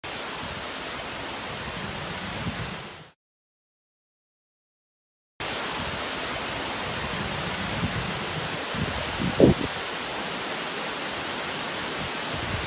Breathing-Reset-with-Nature-Sound.mp3